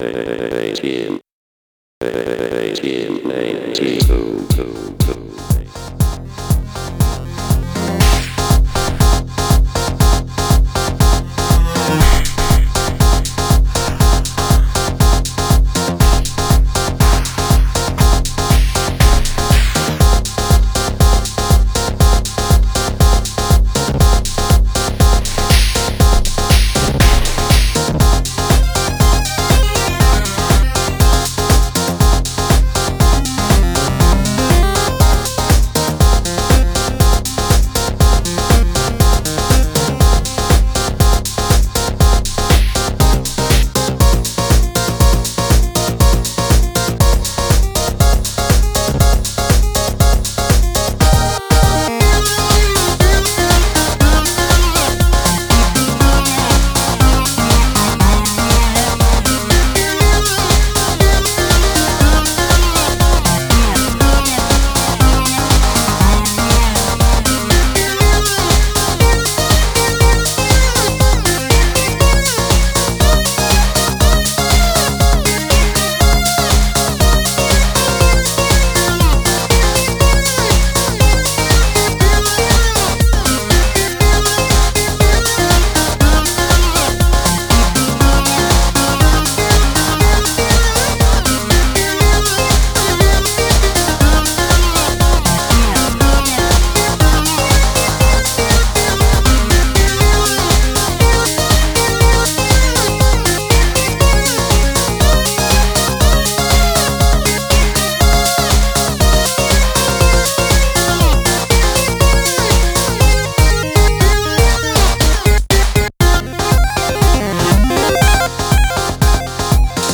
An Amiga MOD collaboration